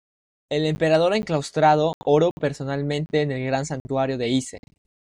per‧so‧nal‧men‧te
/peɾˌsonalˈmente/